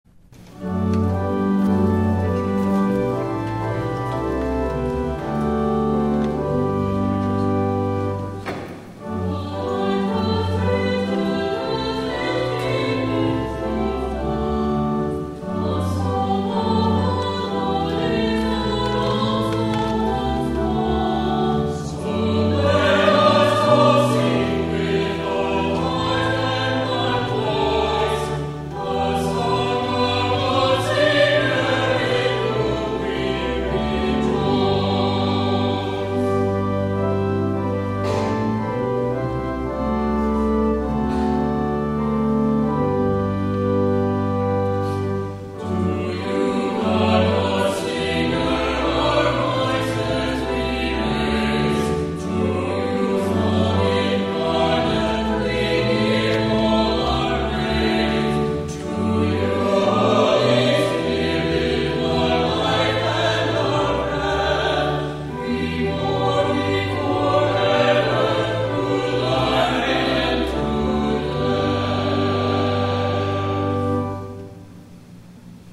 2nd SUNDAY OF EASTER
*THE CHORAL RESPONSE